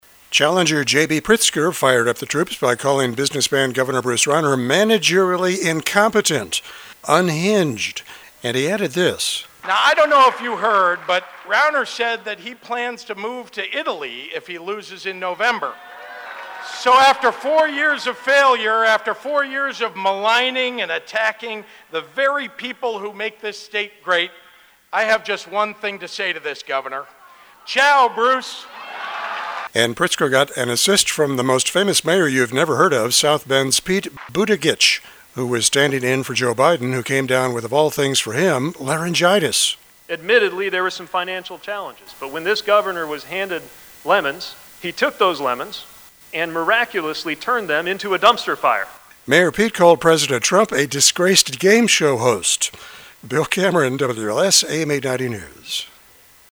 (CHICAGO) Today was Democrats day at the State Fair in Springfield.
Challenger JB Pritzker fired up the troops by calling businessman Gov. Bruce Rauner “managerially incompetent,” “unhinged,” and he added this: